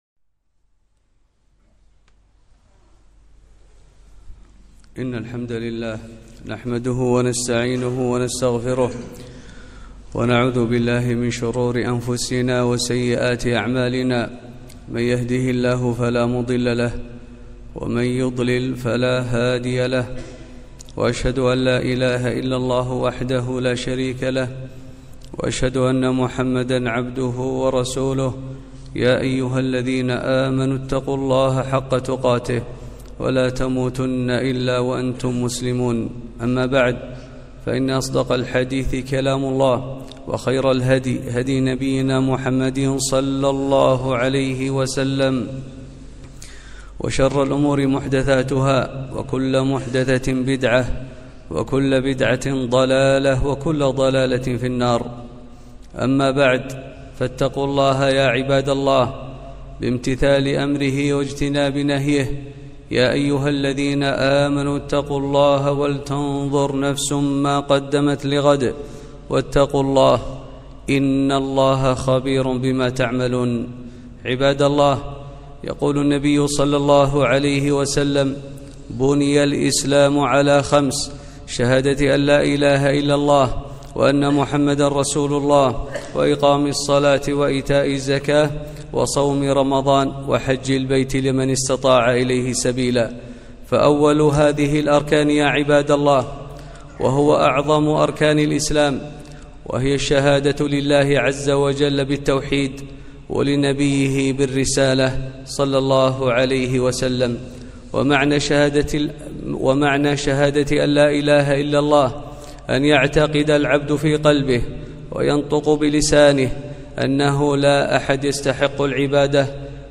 خطبة - تفسير شهادة ألا لا إله إلا الله وأن محمدًا رسول الله ولزوم اتباع السنة